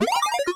Accept8.wav